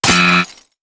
SFX_delivery_fail02.wav